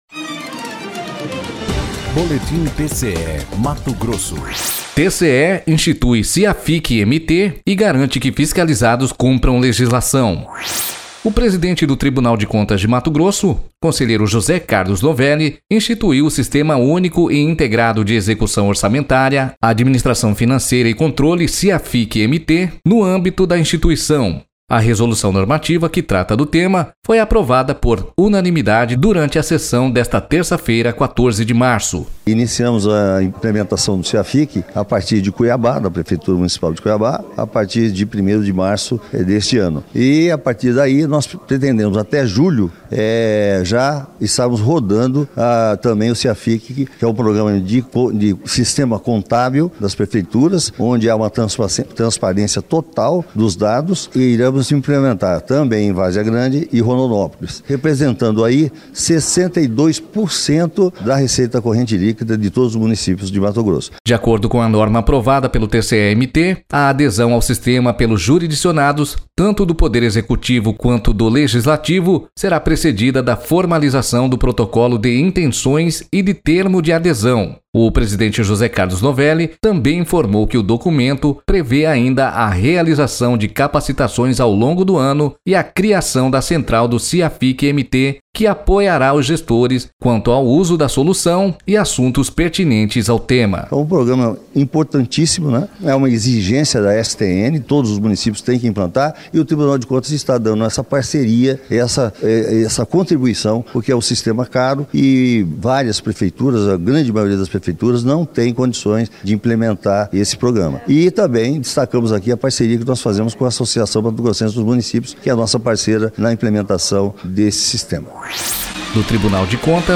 Sonora: José Carlos Novelli – conselheiro presidente do TCE-MT